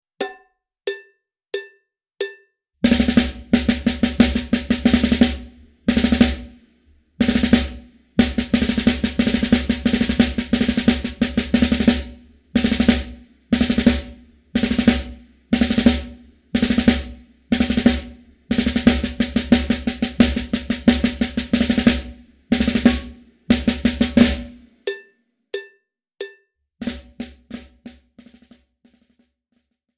Besetzung: Schlagzeug
02 - 5-Stroke-Roll
02_-_5-Stroke-Roll.mp3